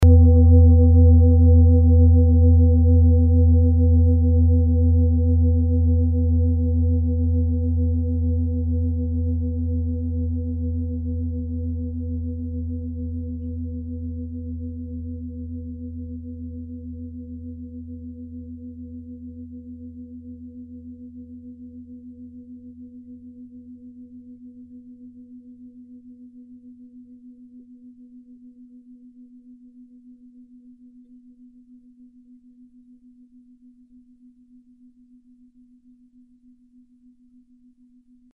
Nepal Klangschale Nr.57, Planetentonschale: Sarosperiode
(Ermittelt mit dem Filzklöppel oder Gummikernschlegel)
Die Klangschale hat bei 81.54 Hz einen Teilton mit einer
Die Klangschale hat bei 242.43 Hz einen Teilton mit einer
In unserer Tonleiter liegt dieser Ton nahe beim "H".
klangschale-nepal-57.mp3